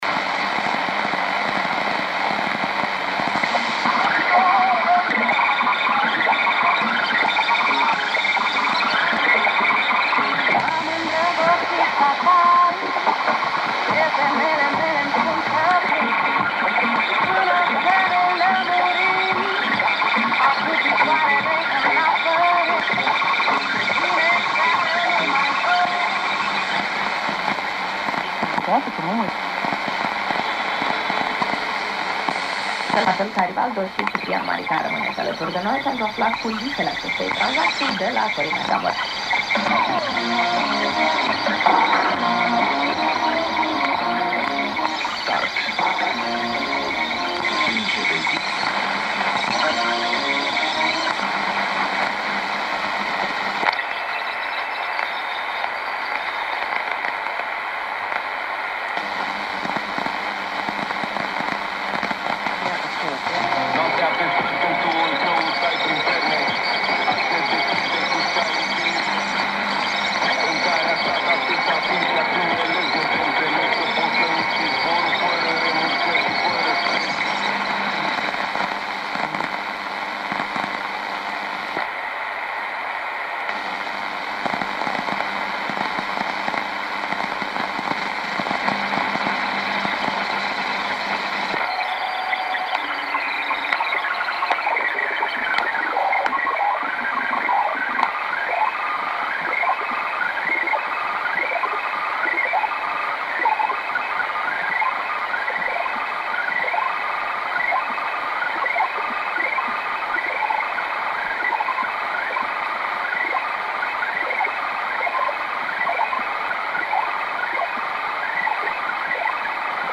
DNA-similar signal with radiostation 1
Начало » Записи » Радиоcигналы на опознание и анализ